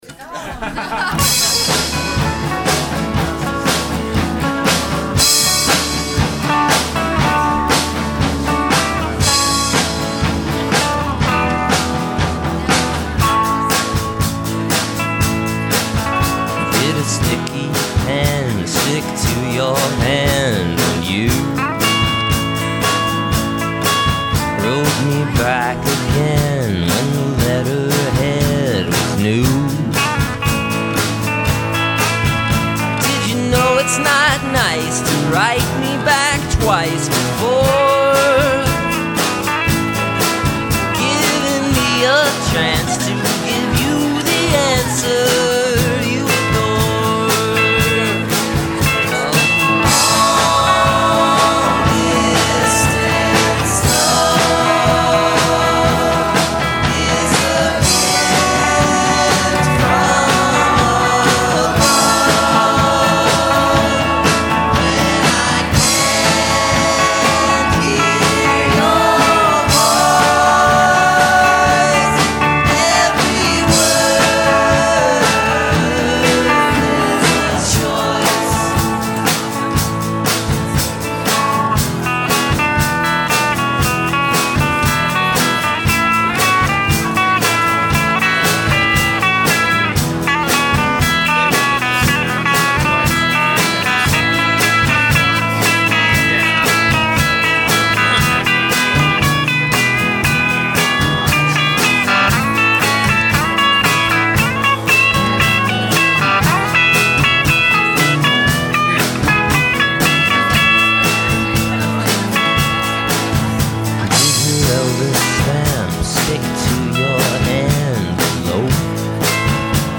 guitar, bass, vocals
drums